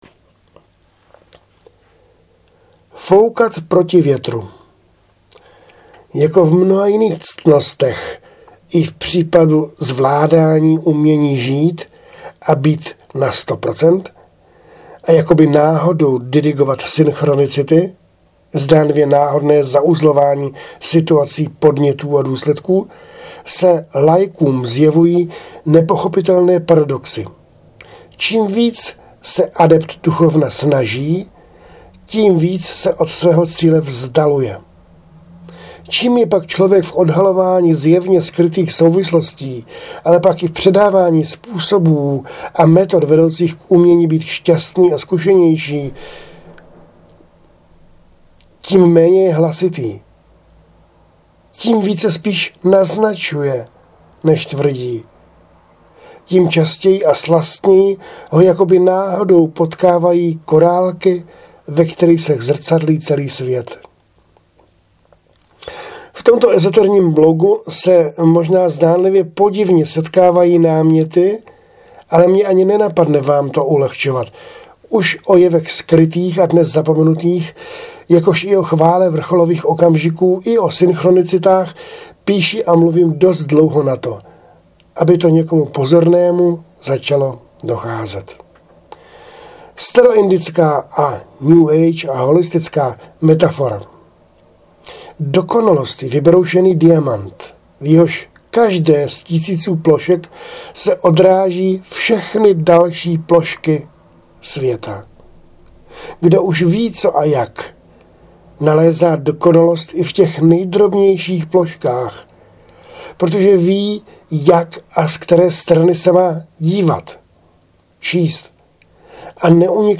Autorsky namluvený fejeton